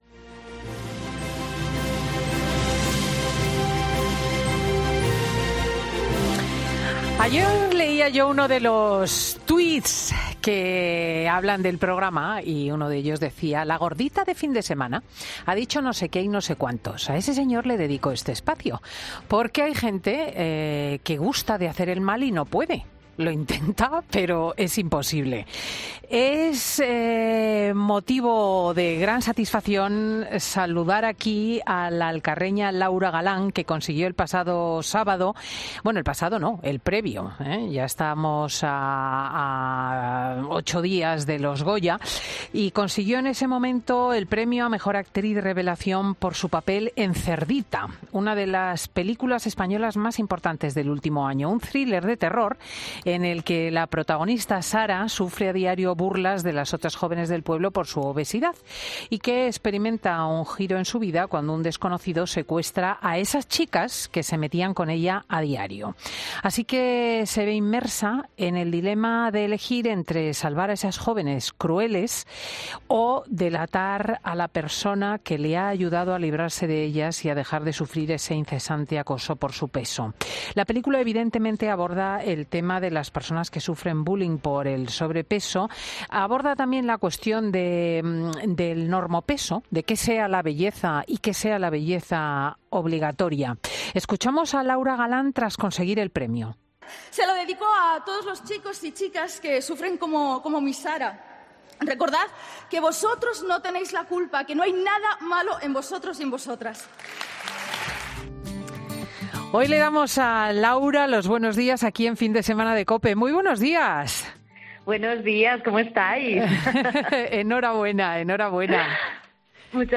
Laura Galán, protagonista de 'Cerdita' y galardonada con el Goya a 'Mejor actriz revelación', ha sido entrevistada en 'Fin de Semana' COPE